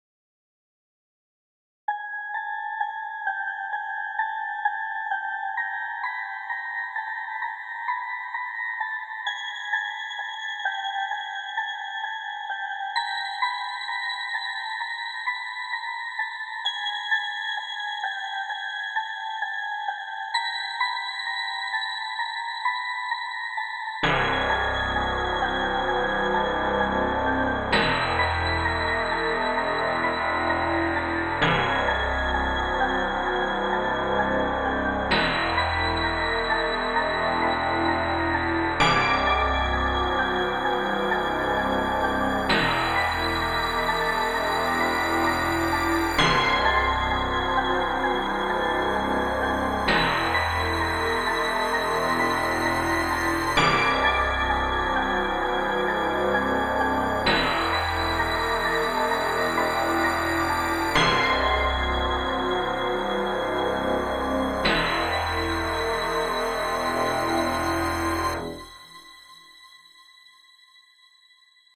Сделал какой-то ужастик.
Horror.mp3